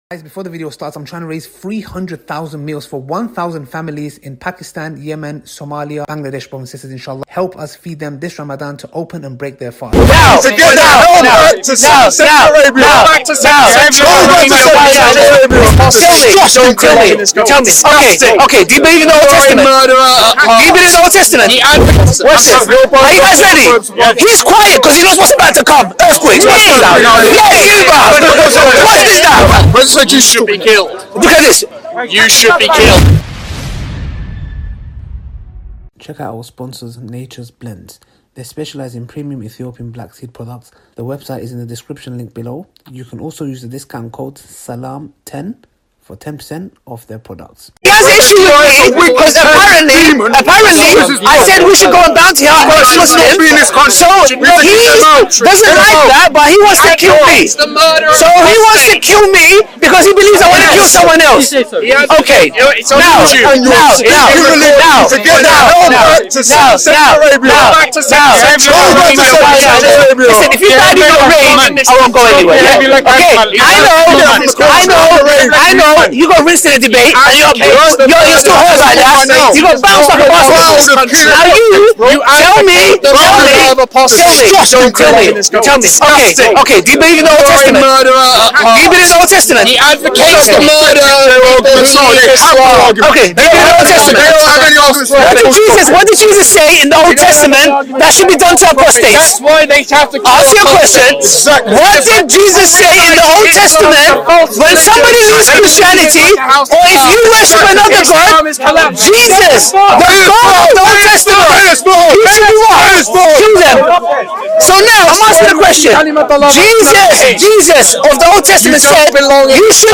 3 CHRISTIANS CORNER MUSLIM THEN RUN - SPEAKERS CORNER.mp3